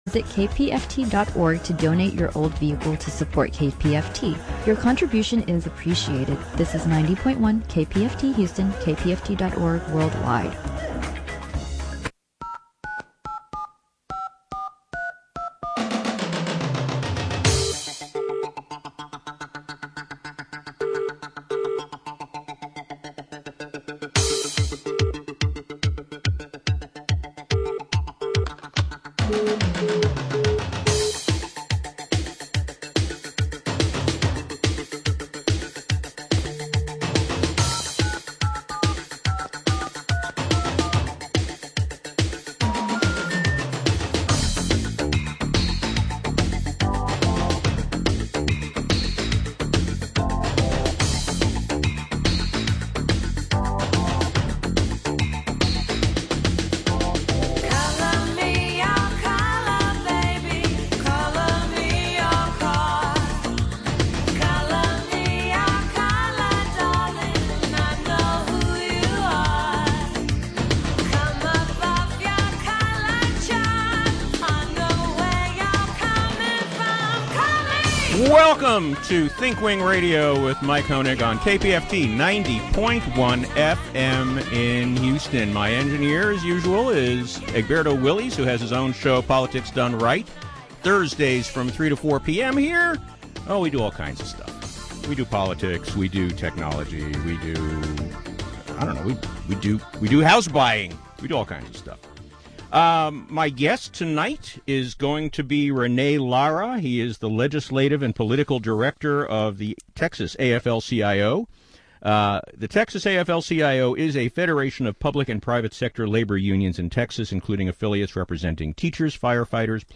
We will be taking callers during this show.